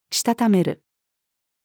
認める-female.mp3